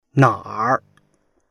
na3r.mp3